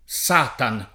S#tan] e Satanas [S#tanaS], quest’ultima con accento spostato nel lat. mediev.